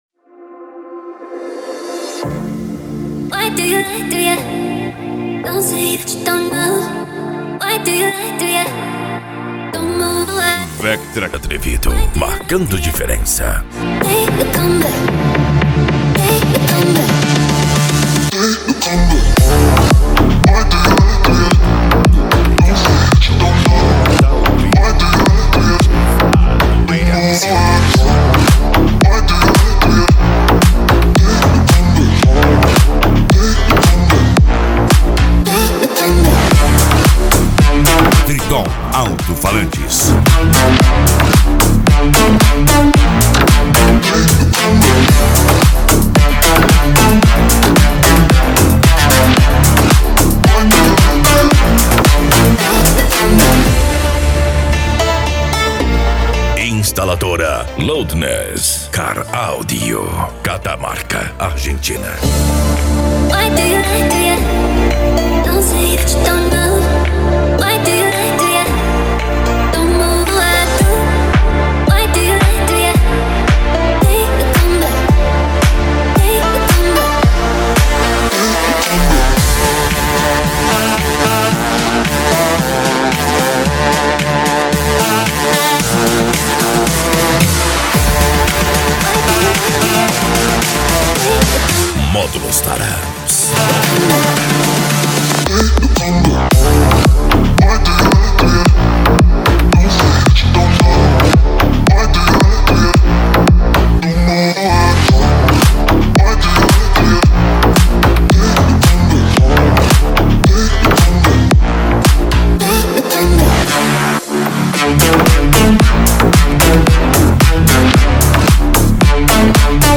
Bass
Cumbia
Musica Electronica
Hip Hop
Remix